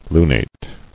[lu·nate]